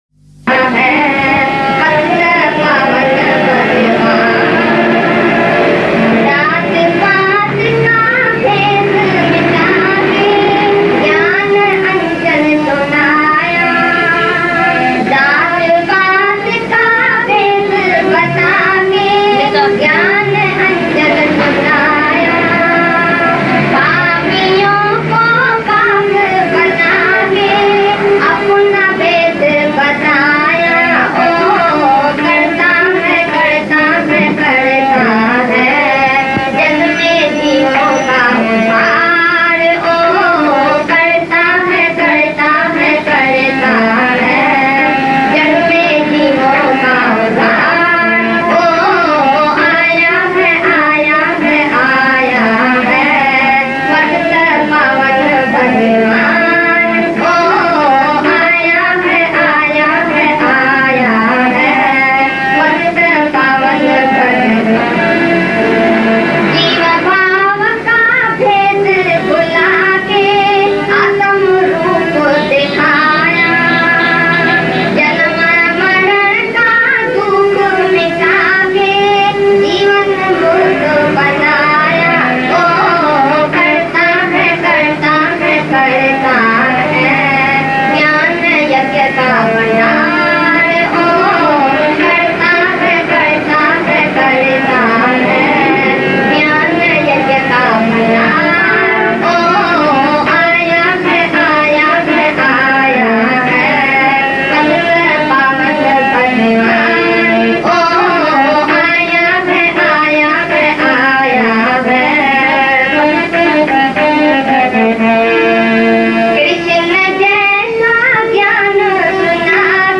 Aaya hai Aaya hai Patit Paawan Bhagwan Bhajan | आया हैआया है, पतित पावन भगवान ओ भजनDivine Geeta Bhagwan Hindi Bhajans